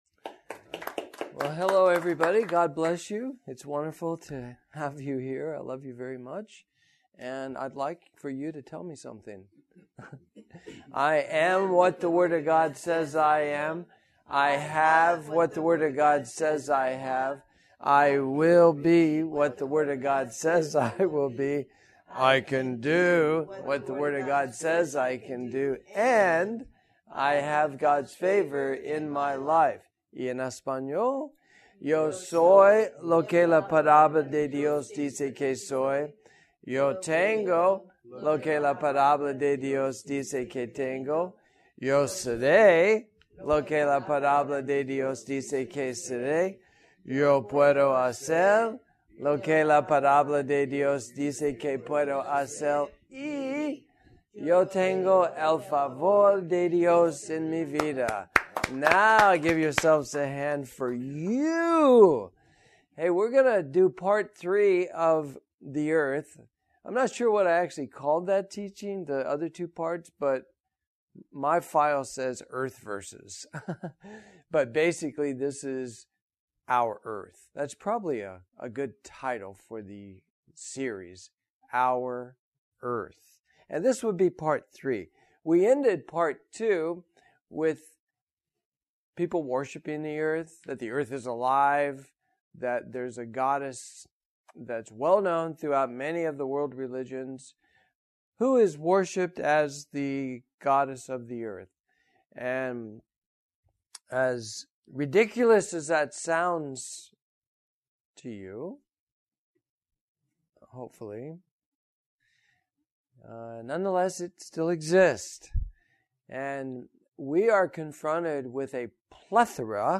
NOTE: This teaching also features some videos, you will hear a tone indicating when to pause and play the following videos: